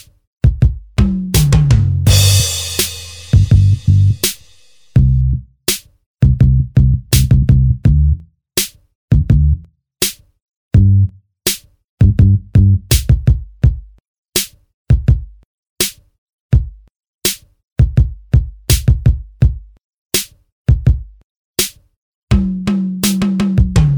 end cut R'n'B / Hip Hop 4:50 Buy £1.50